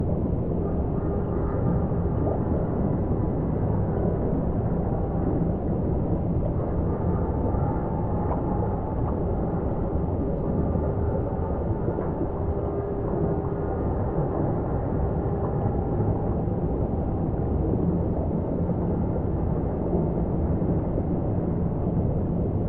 Wreak_ambient_loop.ogg